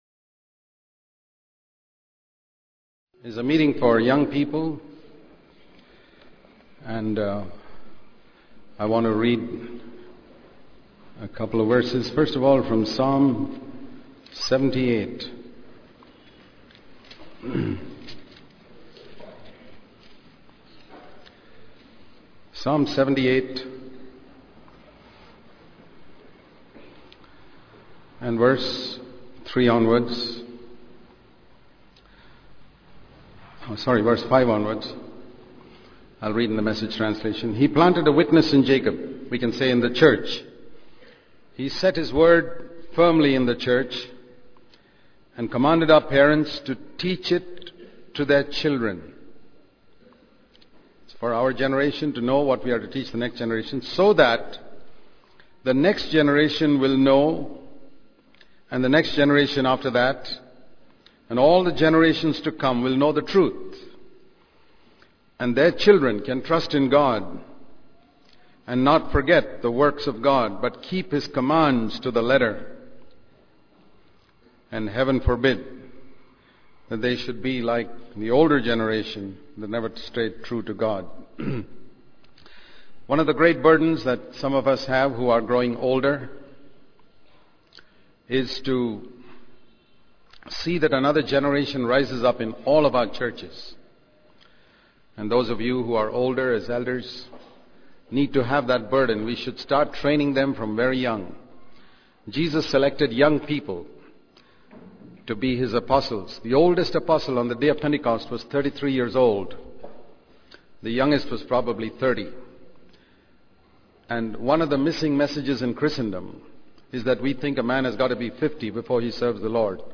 14.The Good Is The Enemy Of The Best The Missing Messages In Today's Christianity Bangalore Conference 2006 sermons.